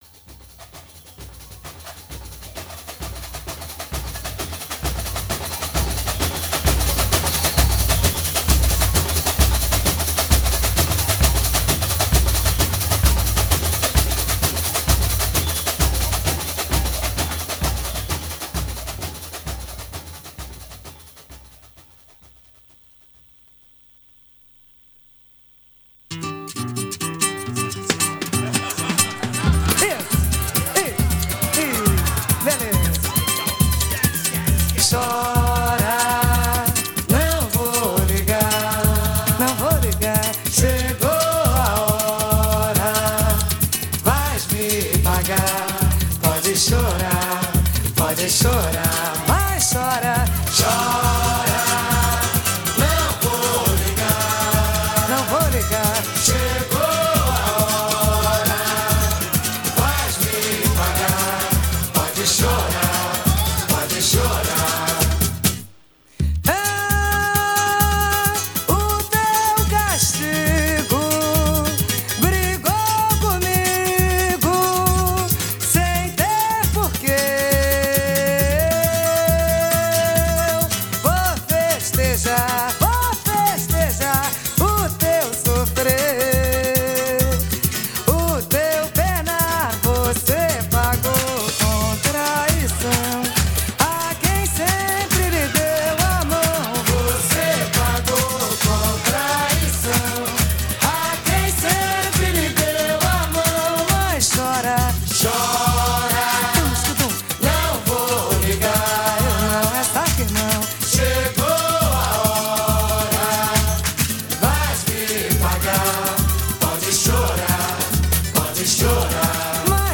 Brazilian carnival music